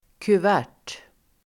Ladda ner uttalet
kuvert substantiv, envelope [letter]Uttal: [kuv'ä:r] Se filmBöjningar: kuvertet, kuvert, kuvertenDefinition: omslag om brevSammansättningar: lönekuvert (pay packet)